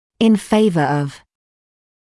[ɪn ‘feɪvə ɔv] [ин ‘фэйвэ ов] в пользу